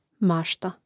màšta mašta